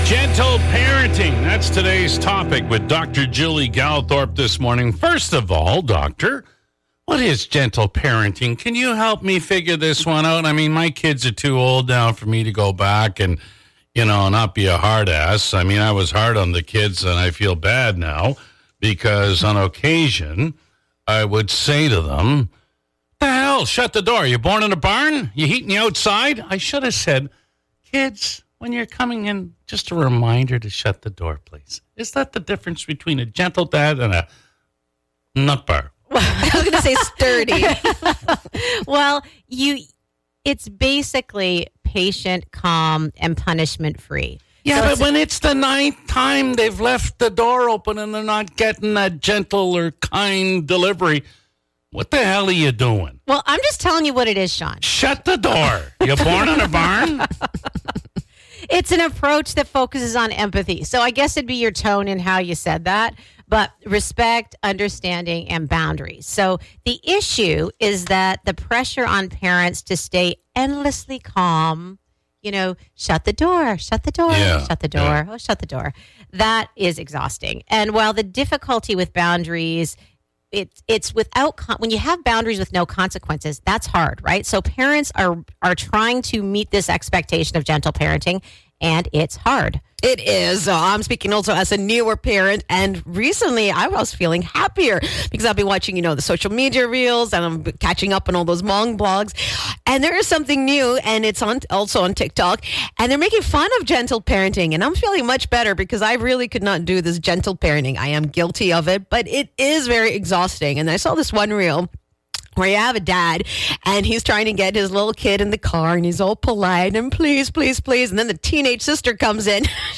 in Studio 97